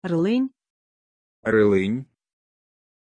Aussprache von Rylyn
pronunciation-rylyn-ru.mp3